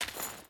Footsteps
Dirt Chain Walk 2.wav